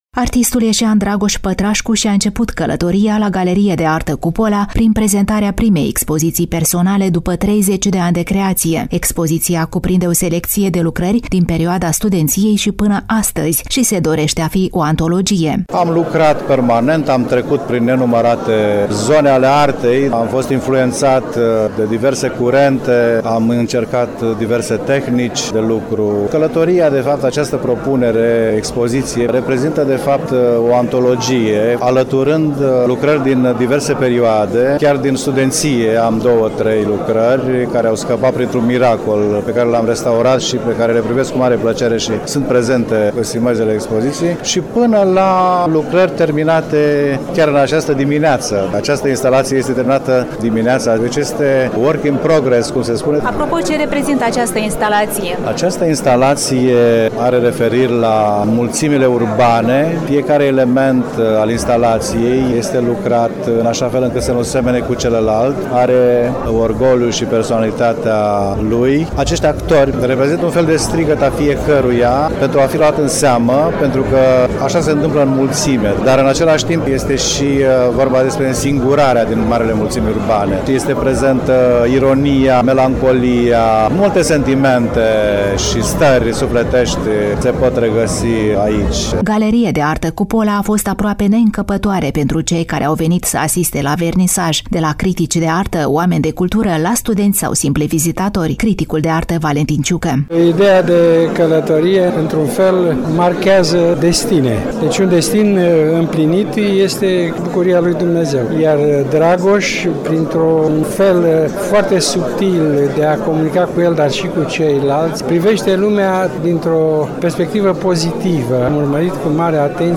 Prima pagină » Rubrici » Reportaj cultural » Călătoria